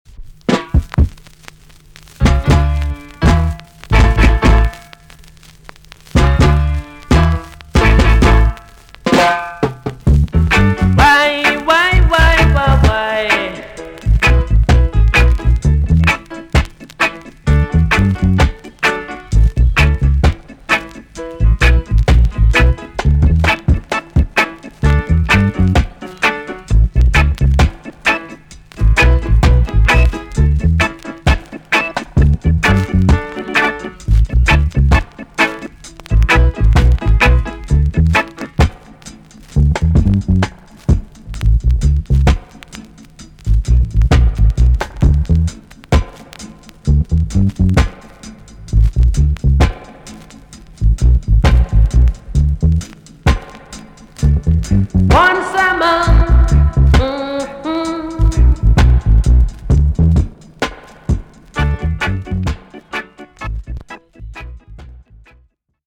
B.SIDE Version
VG+ 少し軽いプチノイズが入ります。
NICE ROCK STEADY TUNE!!